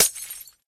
glass1.ogg